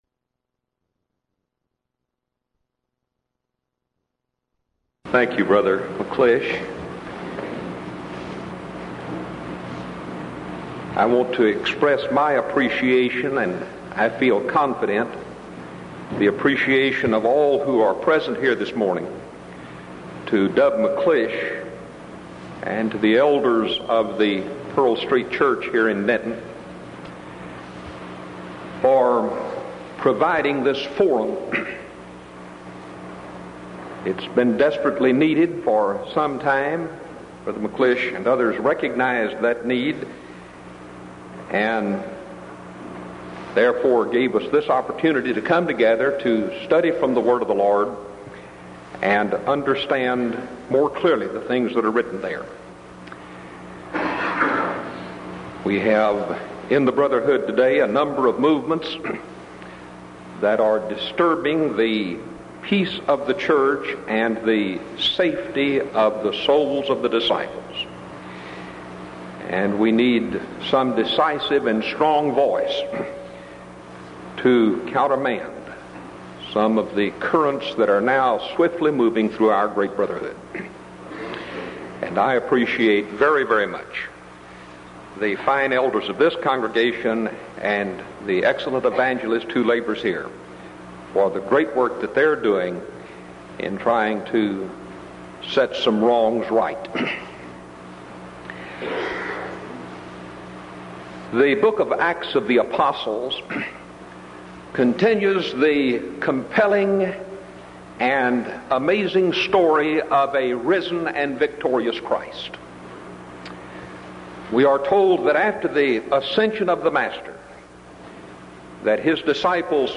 Event: 1985 Denton Lectures